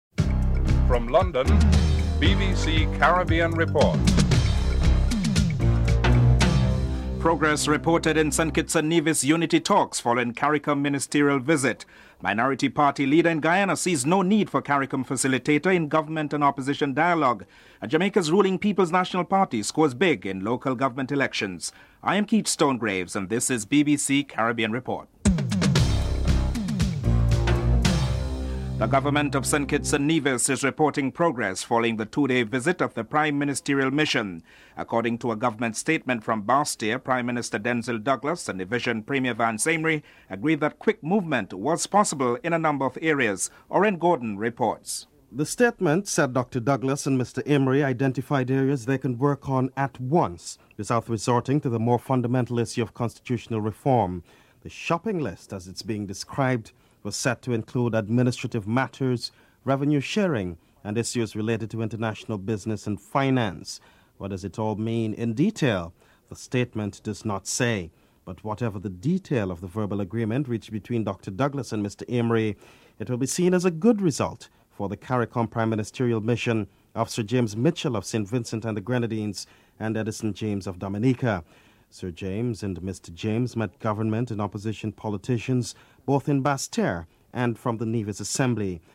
1. Headlines (00:00-00:27)
Caribbean Americans are interviewed (09:38-11:23)